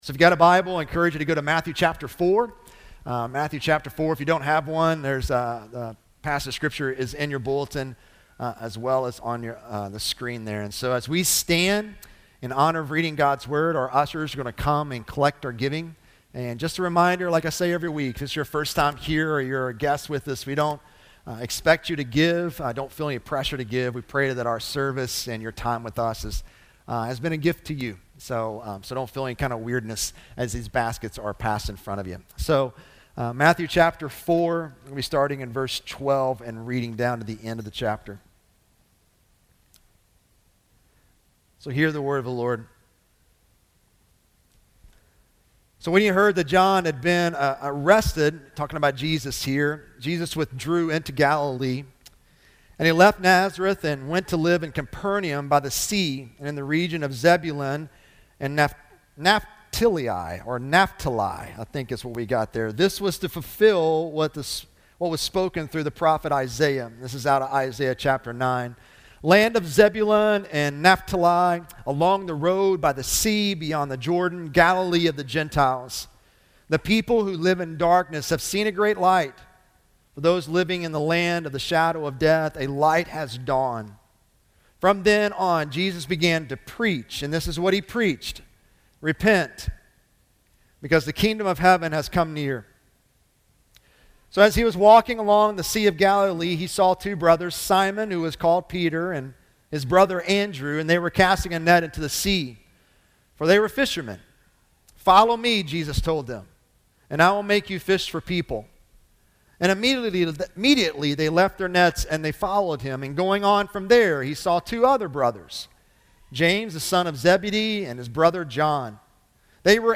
Sojourn Church J‑Town Sermons Podcast